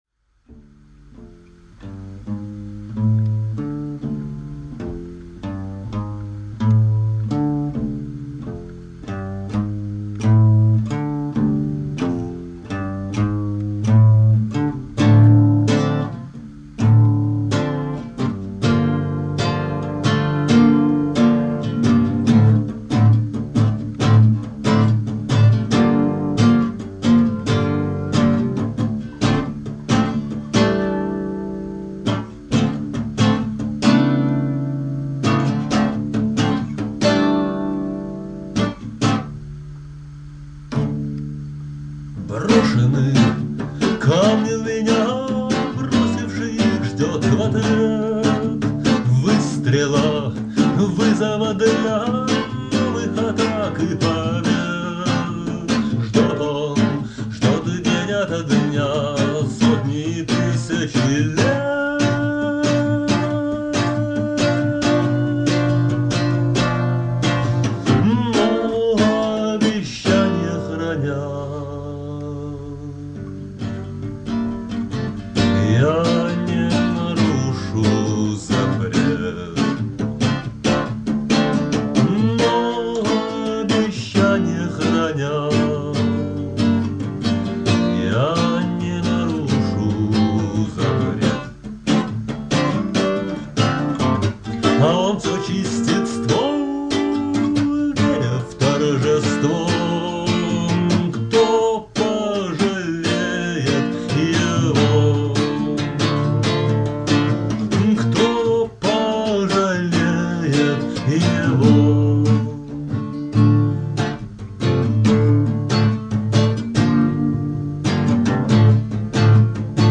песни 1992-97 гг. в исполнении автора.